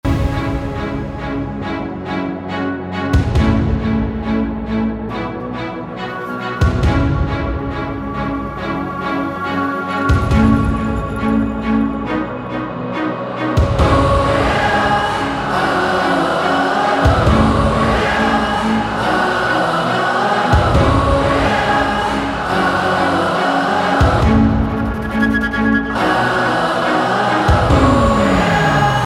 EDM
спокойные
виолончель
хор
Бодрый, смелый рингтон